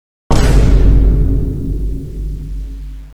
Funny sound effects
vine_boom.wav